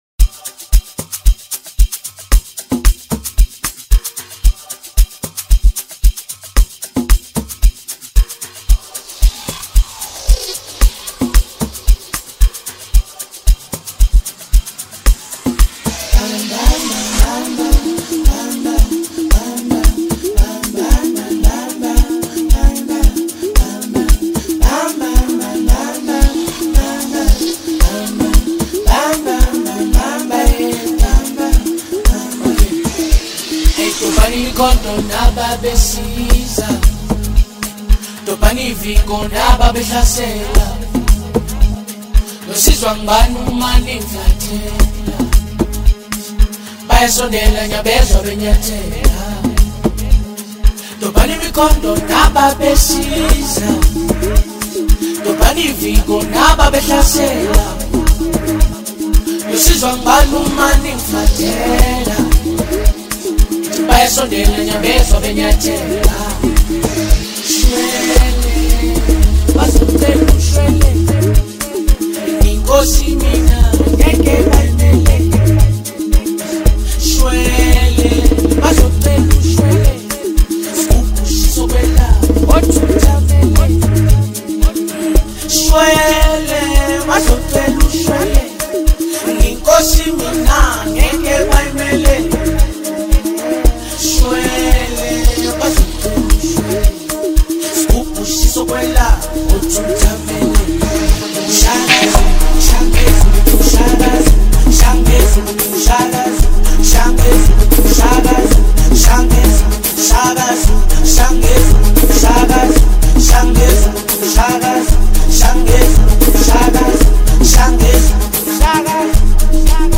You gotta love Amapiano.